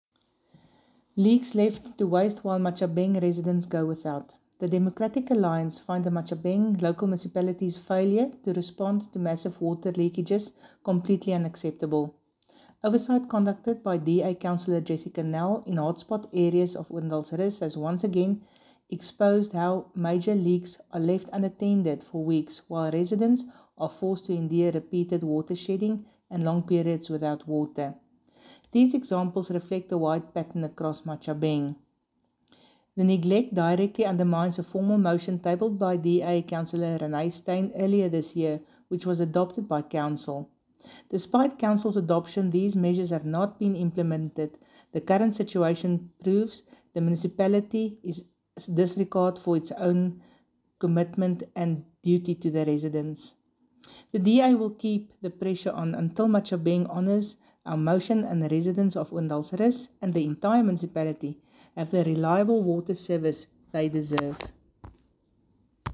Afrikaans soundbites by Cllr Jessica Nel and